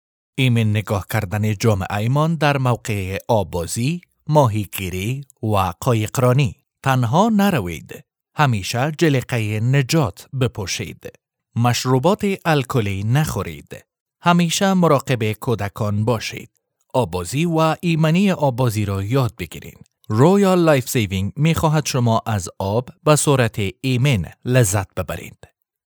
Narration
Male
Adult